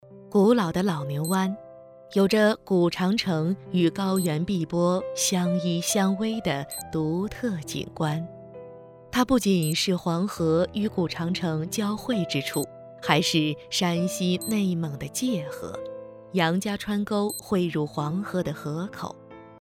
自然诉说 舌尖美食
自然稳重女音，偏年轻。